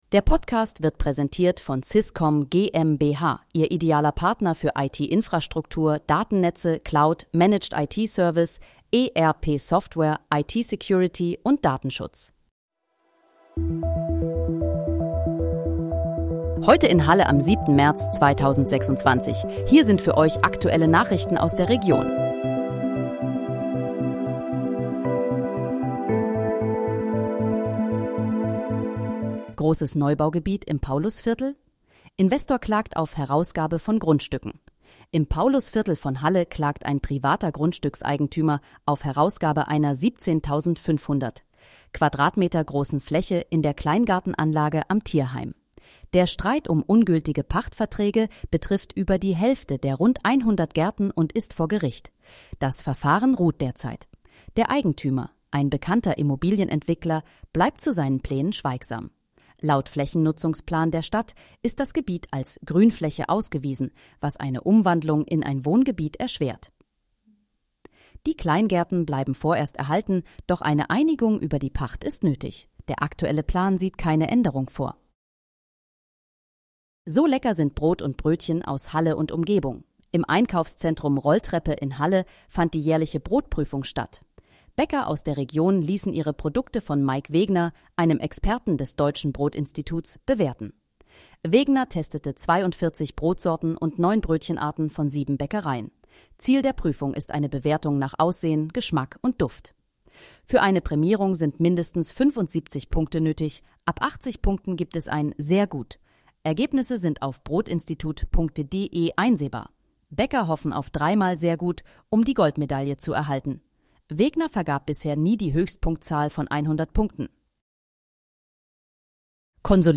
Heute in, Halle: Aktuelle Nachrichten vom 07.03.2026, erstellt mit KI-Unterstützung